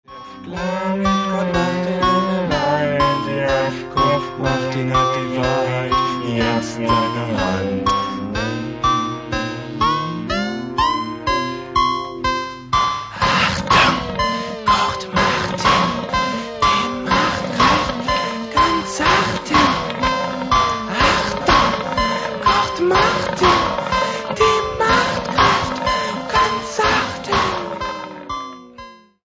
Stilbildend und vorbildlich für Analog und Lo-Tec-Fans.